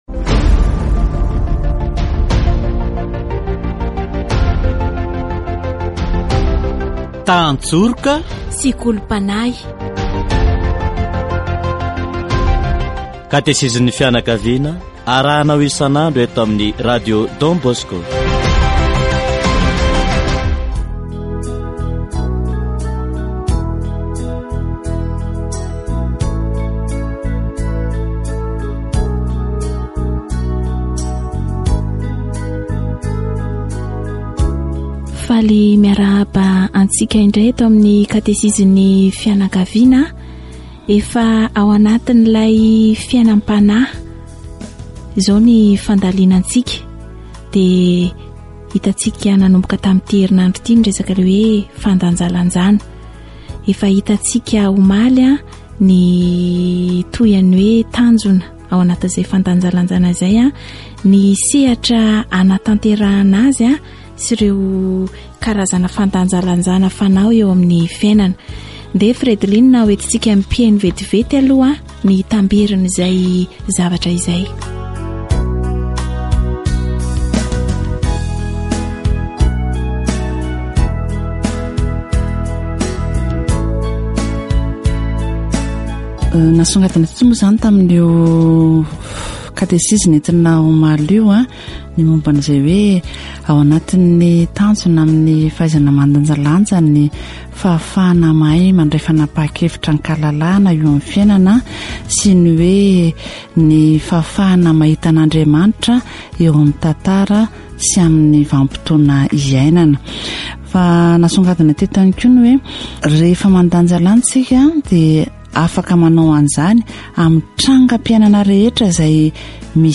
Katesizy momba ny fanomezam-pahasoavana fahaizana mandanjalanja